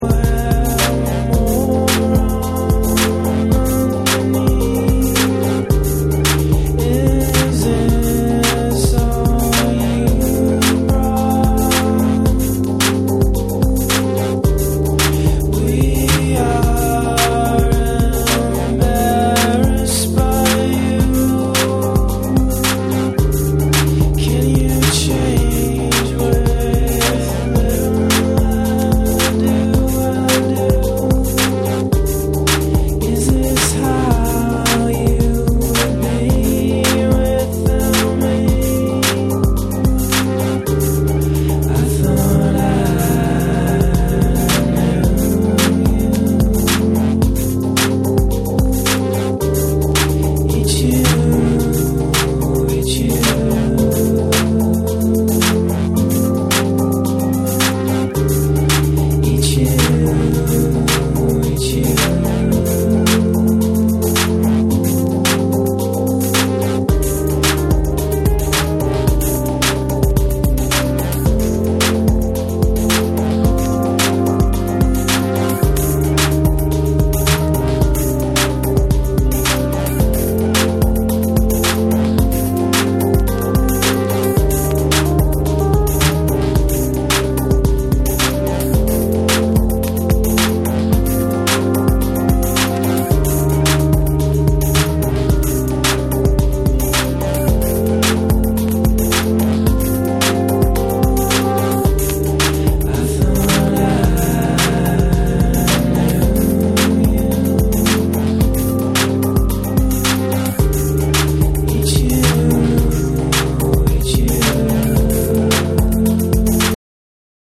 心地よいループと淡々としたグルーヴが織りなすゆったりとしたビートが幻想的な世界観を演出する
BREAKBEATS